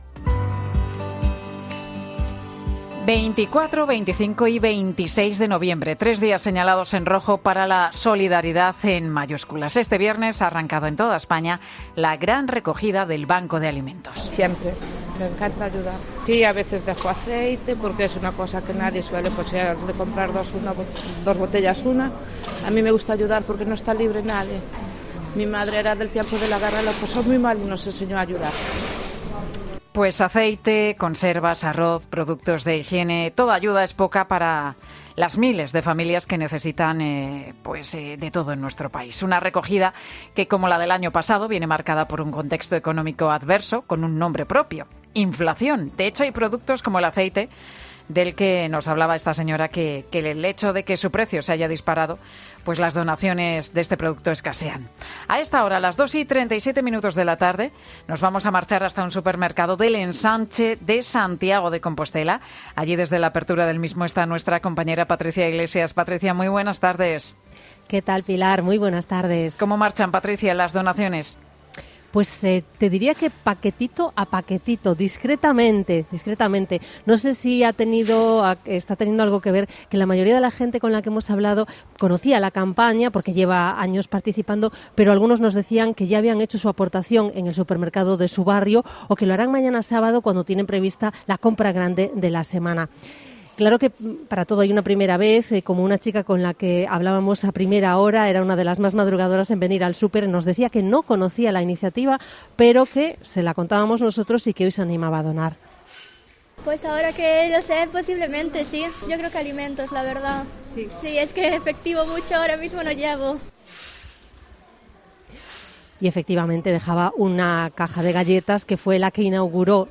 Comienza la Gran Recogida del Banco de Alimentos, Mediodía COPE, testigo en un supermercado